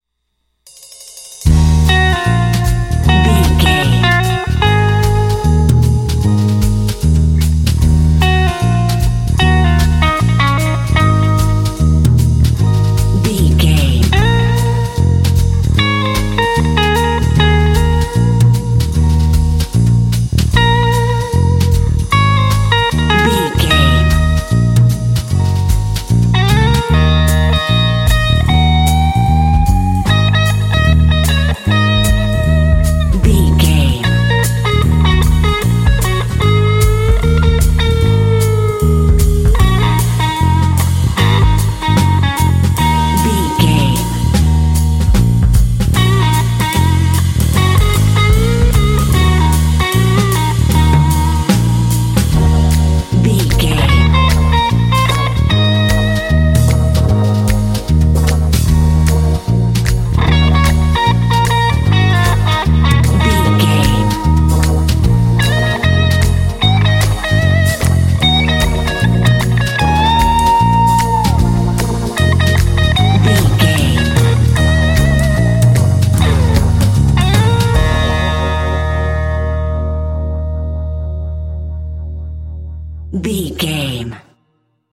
Aeolian/Minor
E♭
dreamy
optimistic
uplifting
bass guitar
drums
electric guitar
synthesiser
jazz
swing